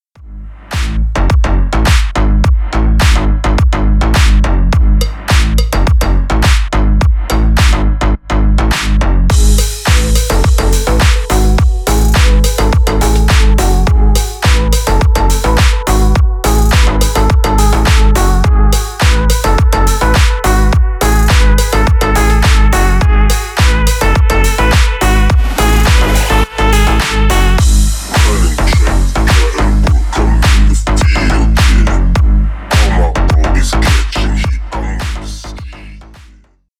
басы
клубные